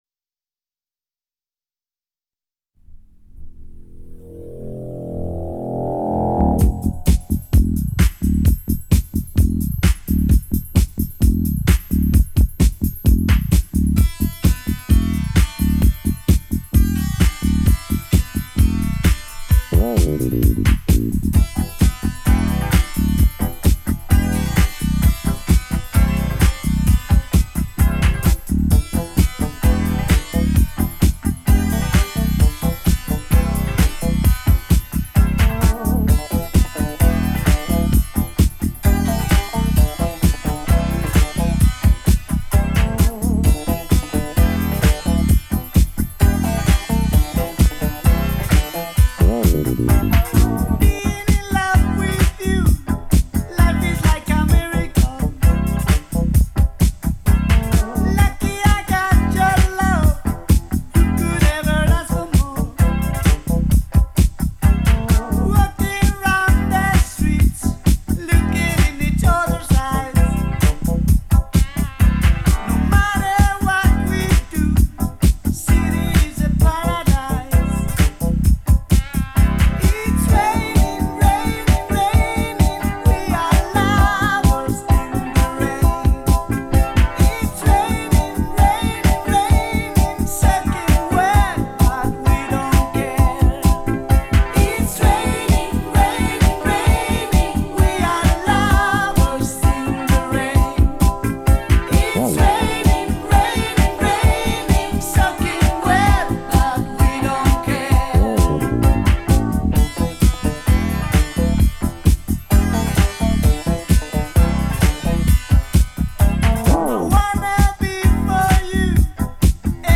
играющая в стиле R & B и Funk Rock